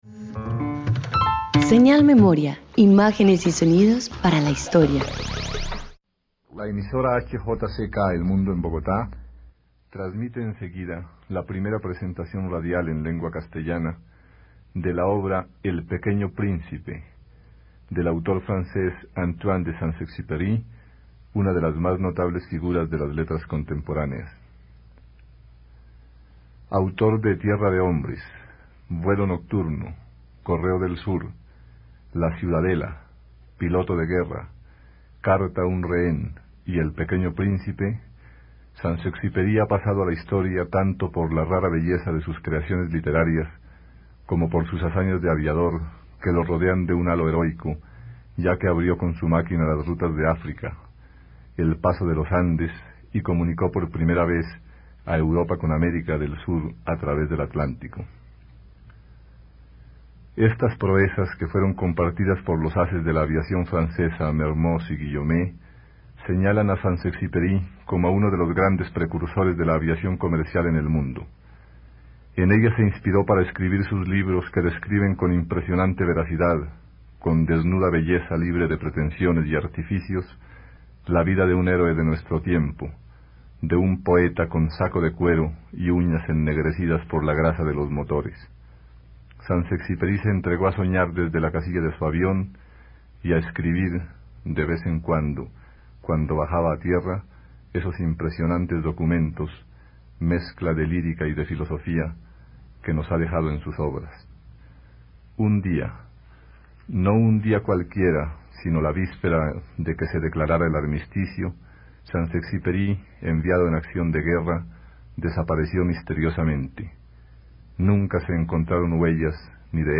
Asset ID 0 Arriba 100% Down 0% Producción El Principito Tags radio radioteatro Adaptación literaria literatura Obra Duración 35Minutos Archivo principito.mp3 (27.91 MB) Número de capítulo 1